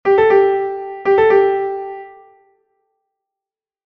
Pralltriller, Notation und Ausführung
Pralltriller (Schneller) heißt die Verzierung, welche aus dem einmaligen schnellen Wechsel der Hauptnote mit der oberen Sekunde besteht und gefordert wird durch [siehe das Zeichen in nachfolgendem Beispiel]:
Der Pralltriller wird immer schnell ausgeführt, löst daher von längeren Noten nur einen kleinen Teil zu Anfang auf:
Pralltriller.mp3